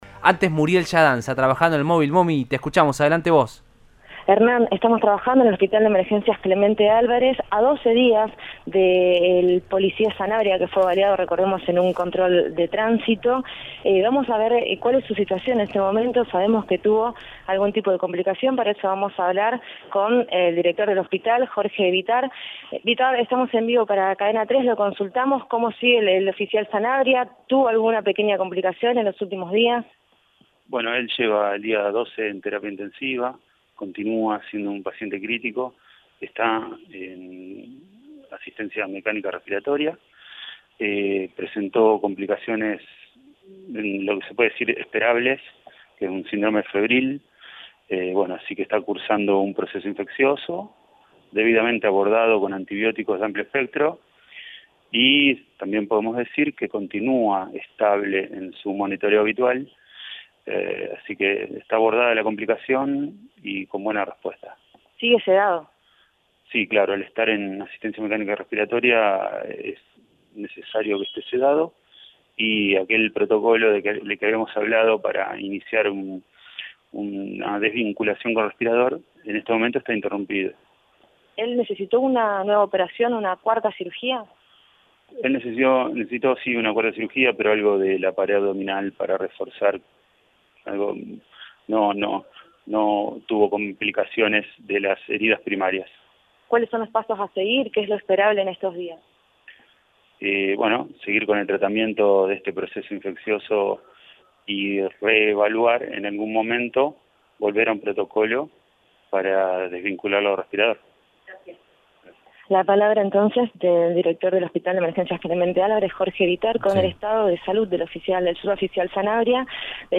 habló con el móvil de Cadena 3 Rosario, en Radioinforme 3